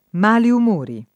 m#li um1ri]: sfogare qualche volta il mal umore lungamente represso [